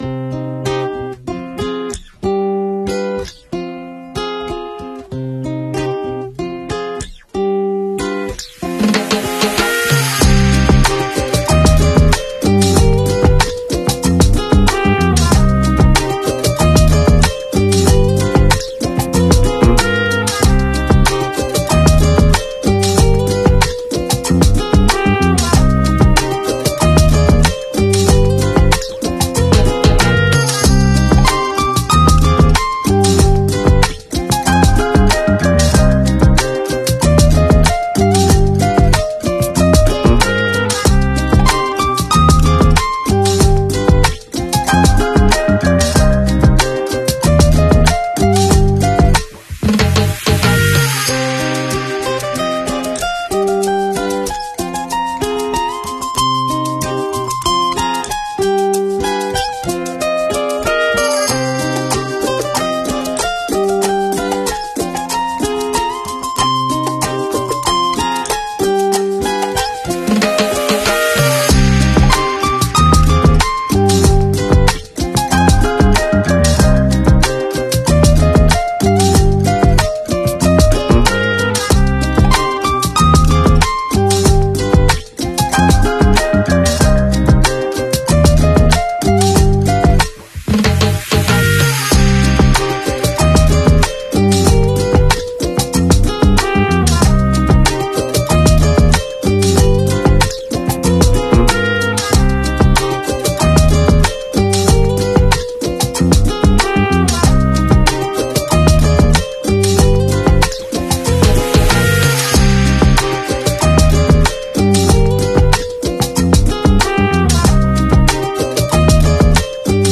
hip-hop instrumental fusion